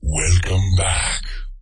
voice_welcomeback.mp3